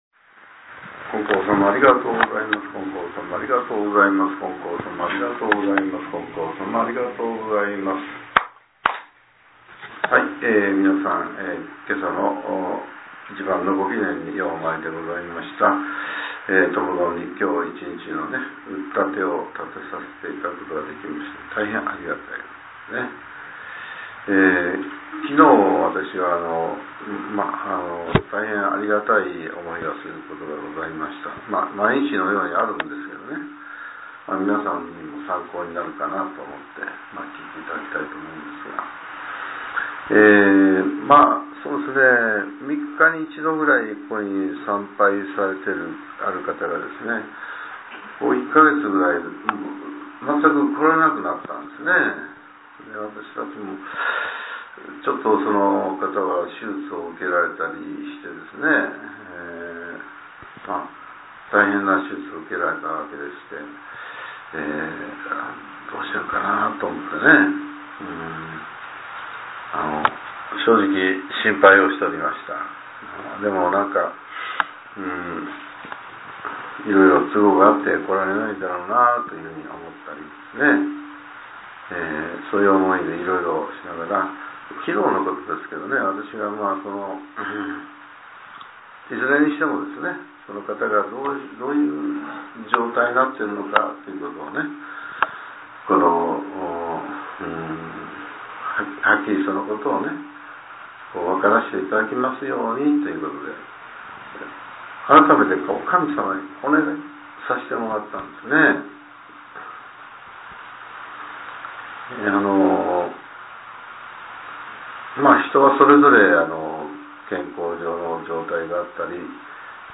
令和７年６月２６日（朝）のお話が、音声ブログとして更新されています。